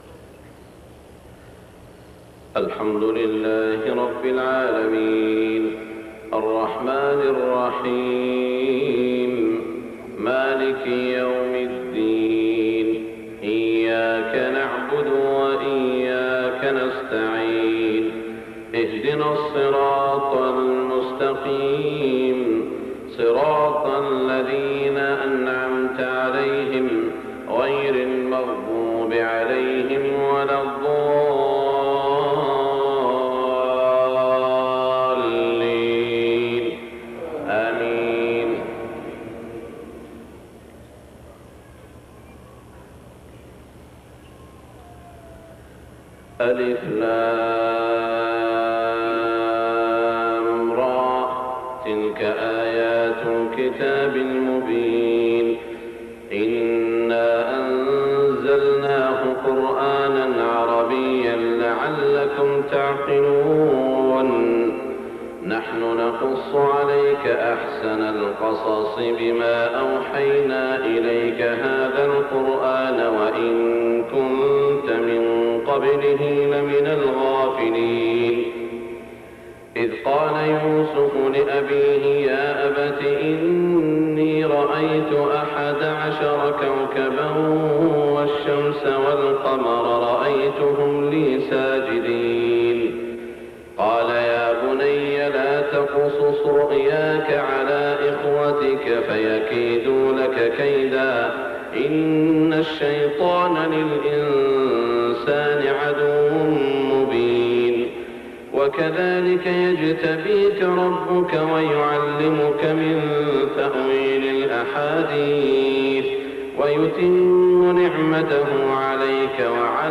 تلاوة خاشعة من سورة يوسف فجريات 1424هـ > 1424 🕋 > الفروض - تلاوات الحرمين